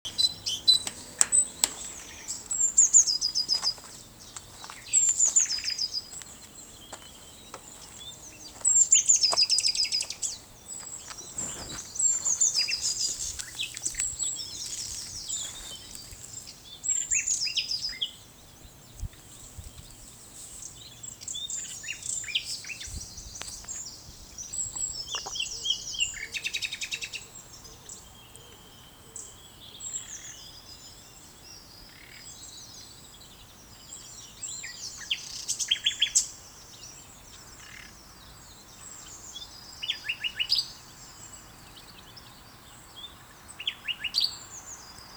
A nightingale sang in Jasper’s Pightle, April 2026